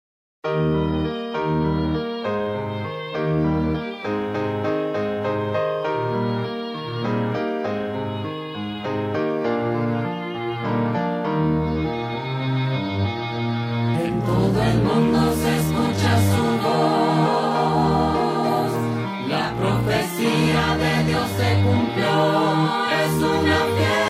primer CD coral